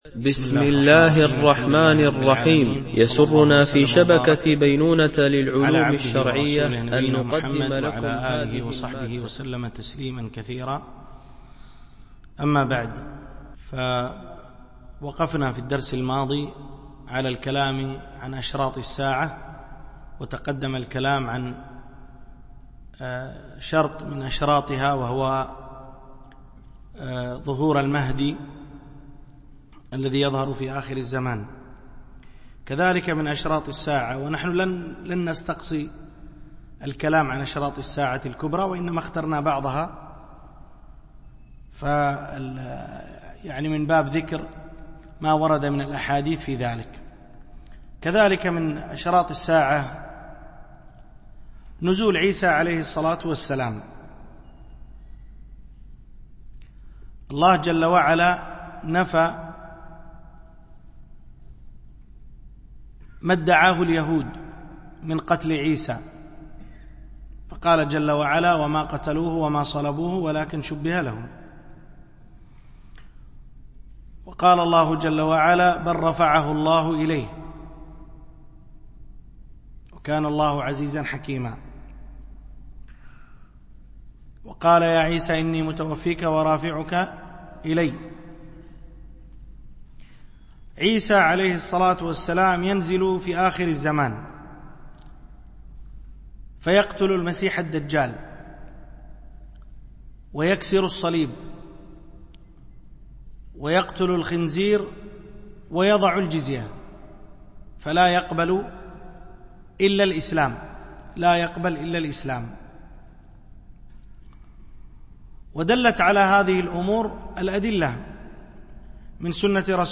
شرح حديث جبريل في بيان مراتب الدين - الدرس 11